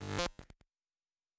pgs/Assets/Audio/Sci-Fi Sounds/Electric/Glitch 1_05.wav at 7452e70b8c5ad2f7daae623e1a952eb18c9caab4
Glitch 1_05.wav